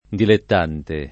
[ dilett # nte ]